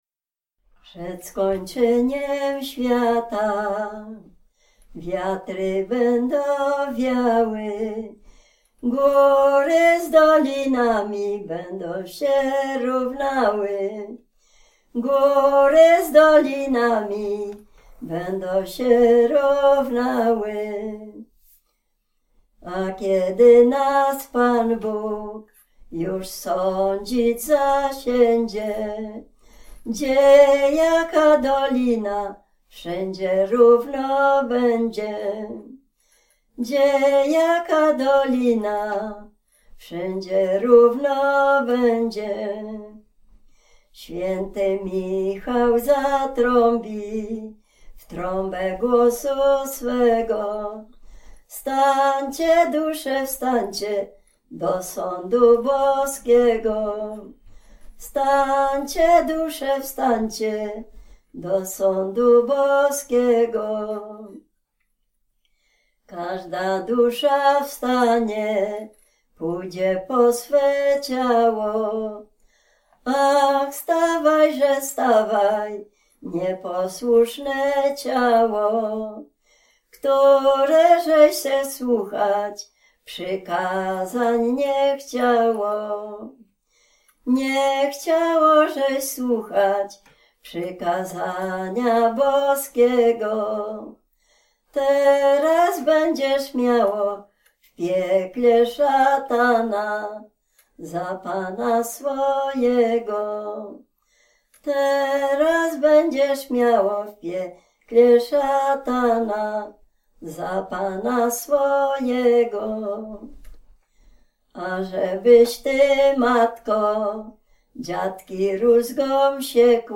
Dolny Śląsk, powiat bolesławiecki, gmina Nowogrodziec, wieś Zebrzydowa
Ballada
ballady dziadowskie